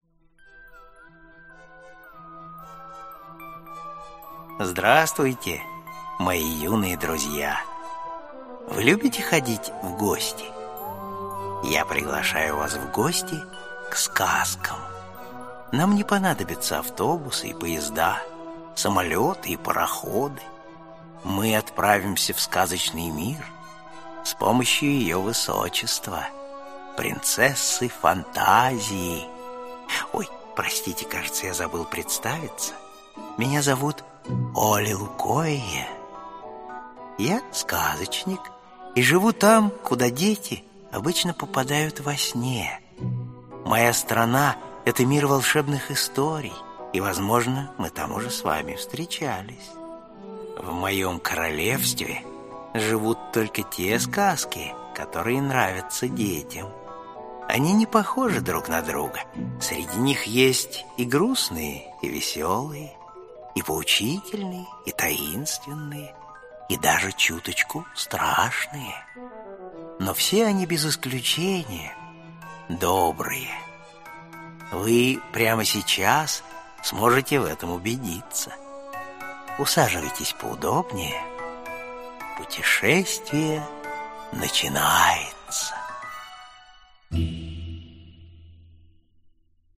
Аудиокнига Карлик Нос | Библиотека аудиокниг